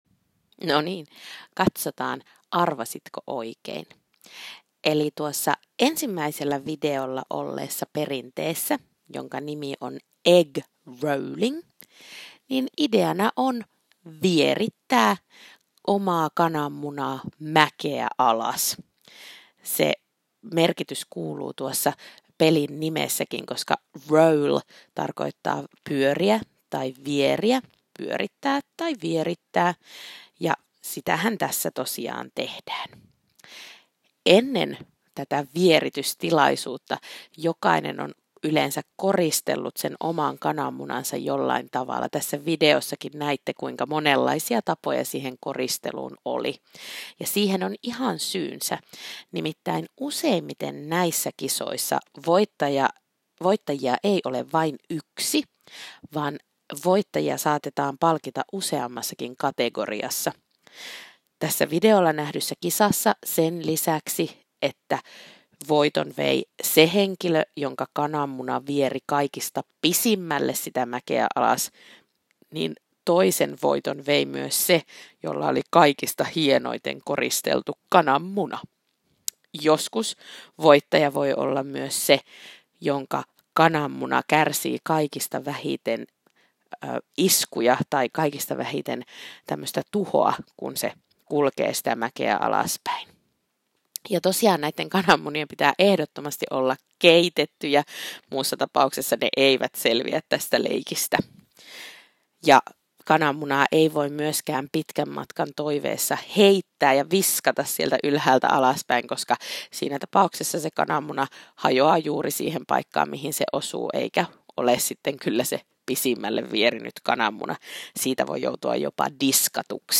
* Kuuntele sitten lopuksi opettajan lyhyt kuvaus näistä pääsiäisiperinteistä.